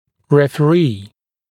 [ˌrefə’riː][ˌрэфэ’ри:]рецензент